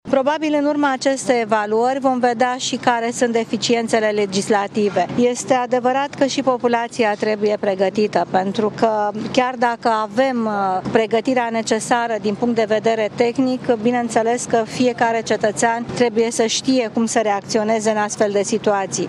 Simularea unui cutremur va permite identificarea eventualelor deficienţe organizatorice şi legislative, dar este util şi pentru ca populaţia să ştie ce are de făcut în caz de cutremur, a declarat premierul Viorica Dăncilă: